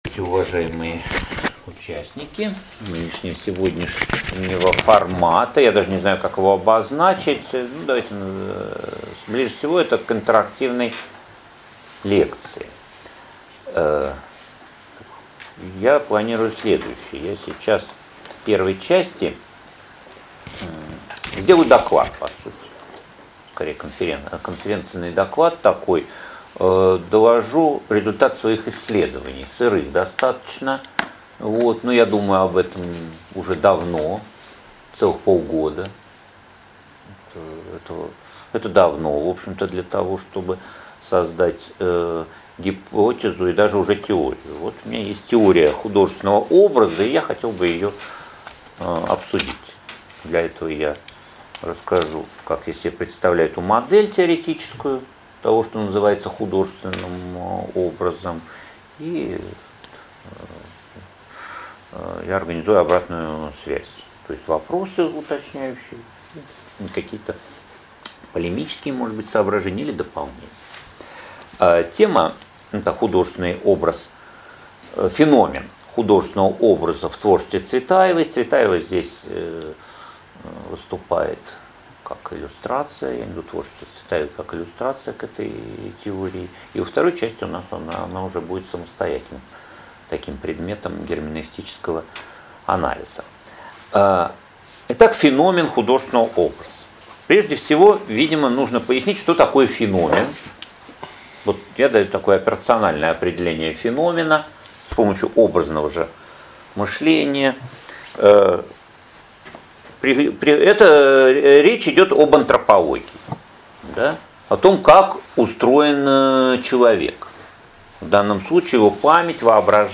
Аудиокнига Феномен художественного образа в поэзии Цветаевой | Библиотека аудиокниг